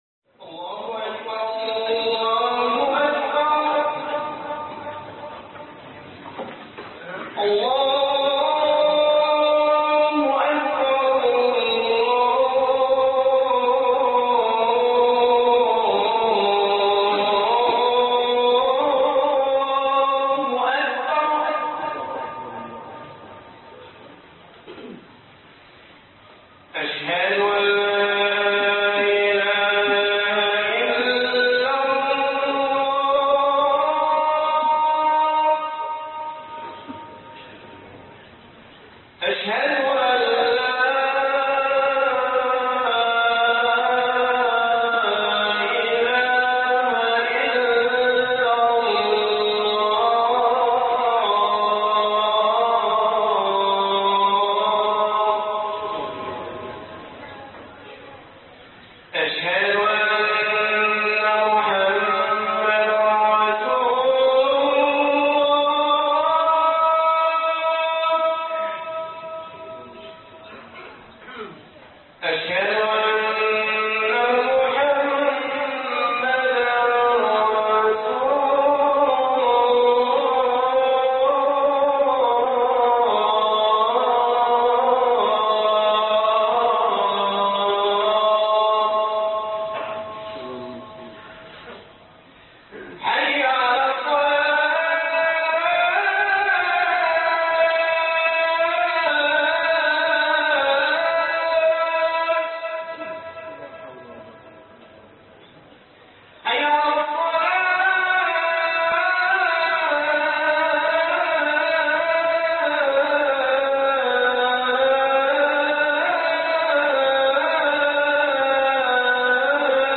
خطب الجمعه